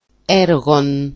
SOUND EXAMPLES in MODERN GREEK